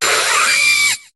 Cri de Fermite dans Pokémon HOME.